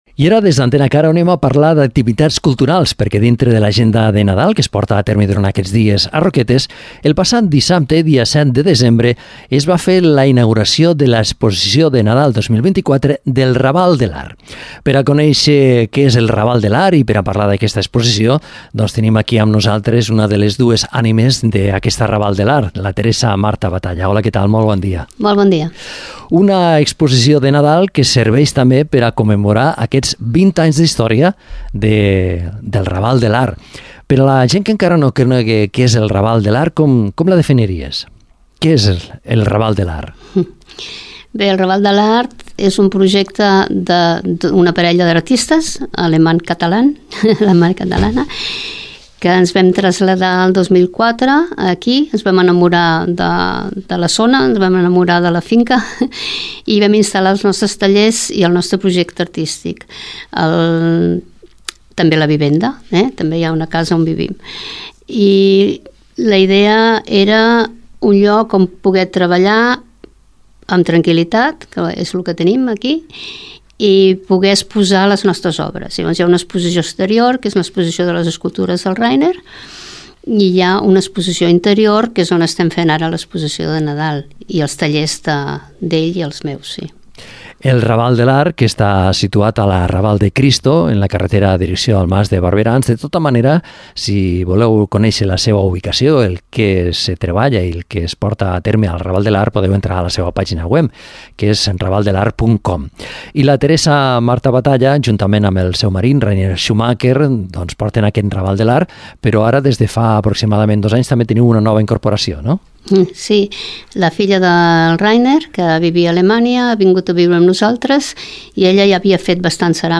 Entrevistem